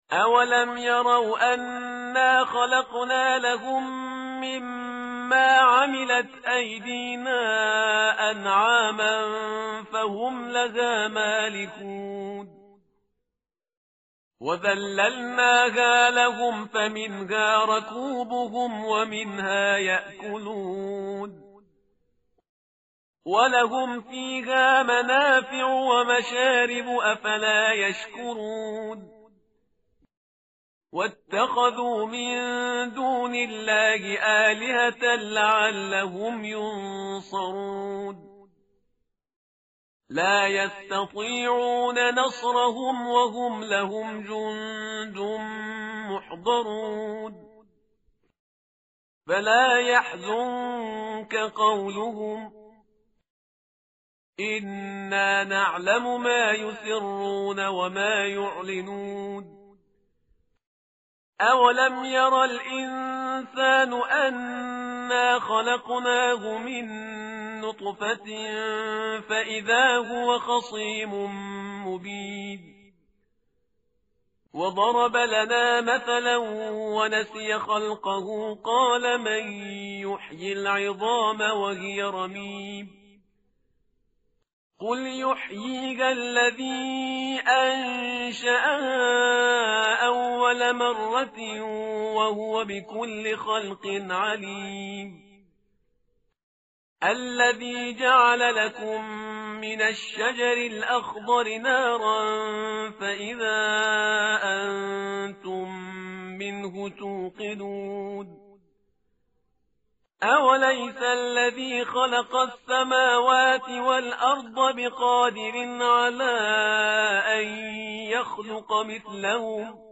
متن قرآن همراه باتلاوت قرآن و ترجمه
tartil_parhizgar_page_445.mp3